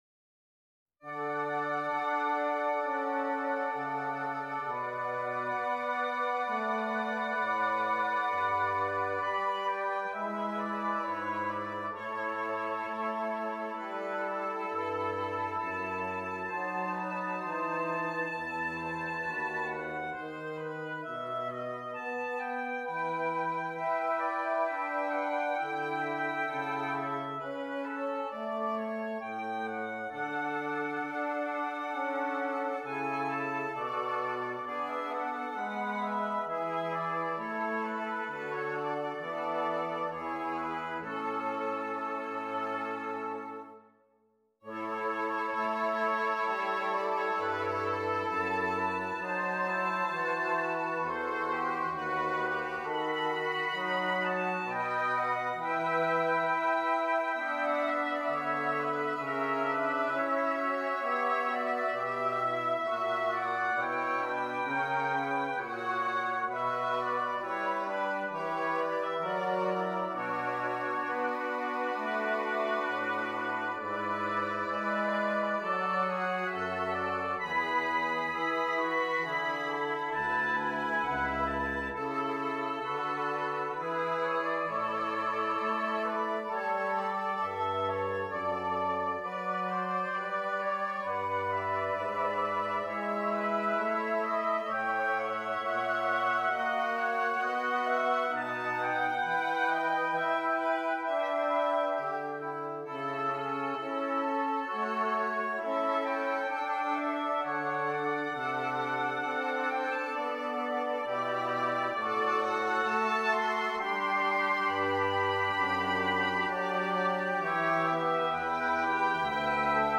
Double Reed Ensemble